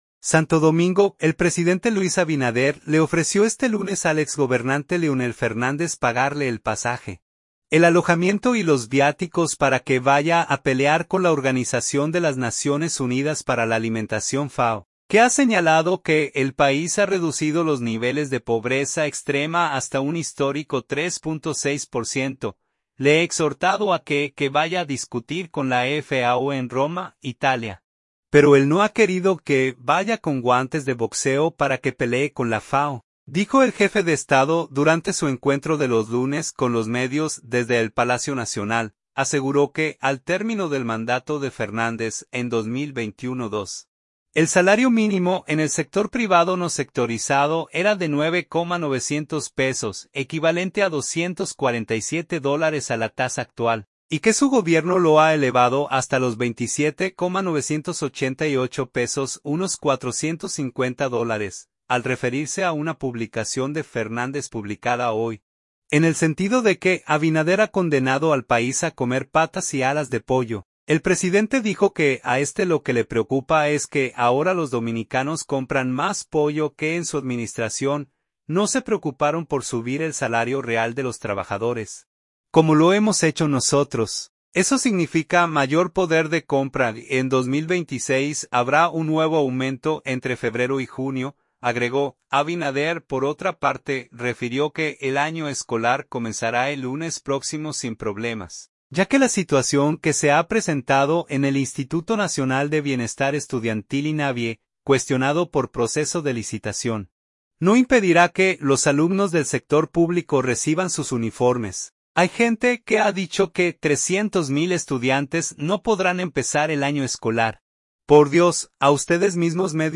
"Le he exhortado a que que vaya a discutir con la FAO en Roma (Italia), pero él no ha querido (…) que vaya con guantes de boxeo para que pelee con la FAO", dijo el jefe de Estado durante su encuentro de los lunes con los medios desde el Palacio Nacional.